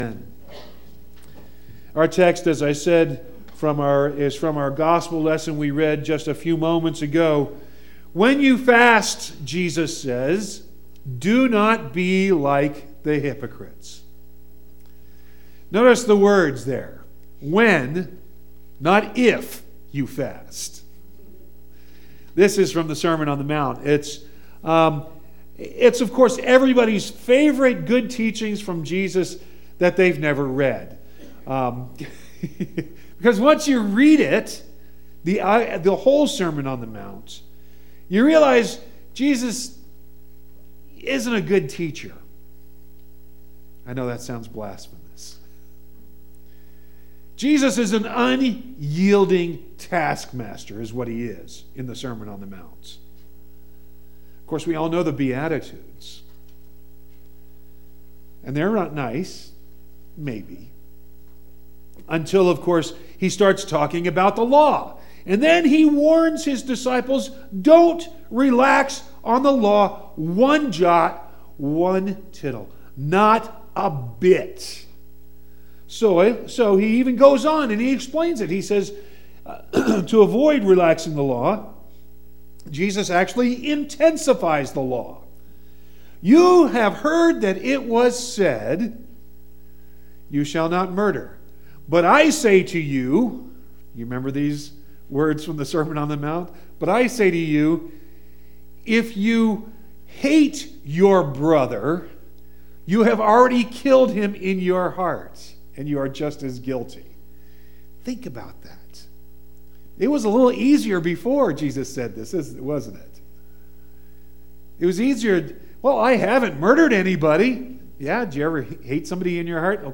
Ash Wednesday 3.06.19